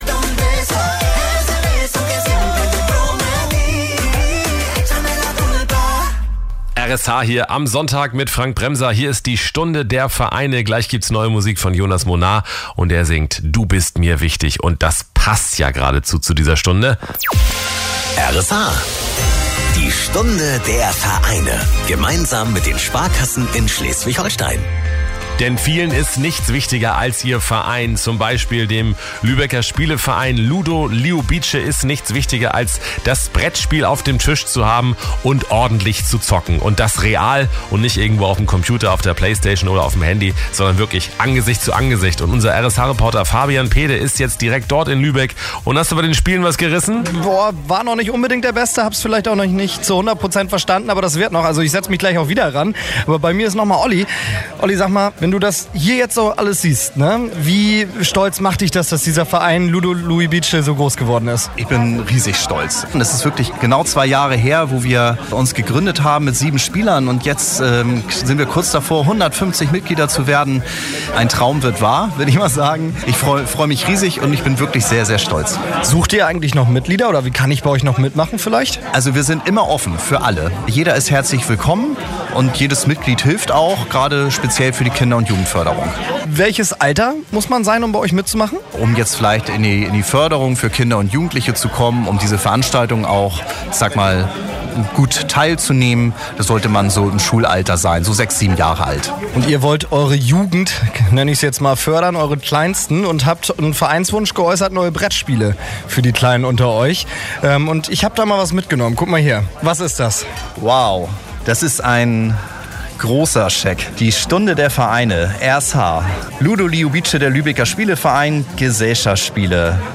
Die Stunde der Vereine” läuft seit einiger Zeit immer Sonntags zwischen 12 Uhr und 13 Uhr! Am 9. September 2018 wurden wir als Spieleverein bedacht und erhielten Besuch von R.S.H. und der Sparkasse zu Lübeck.